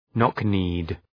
Προφορά
{,nɒk’ni:d}